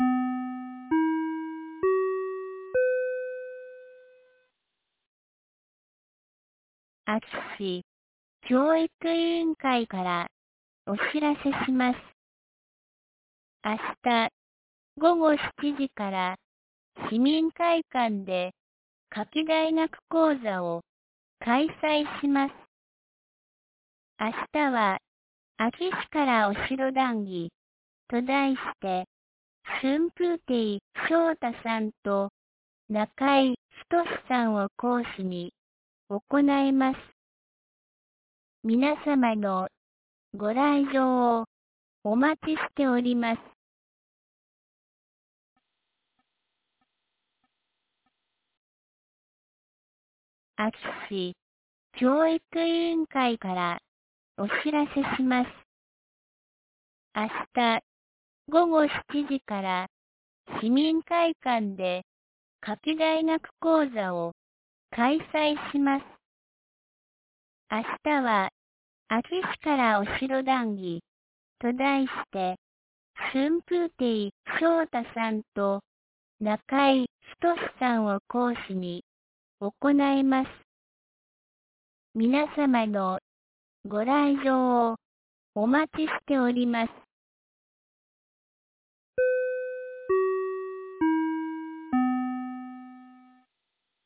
2024年08月20日 17時41分に、安芸市より全地区へ放送がありました。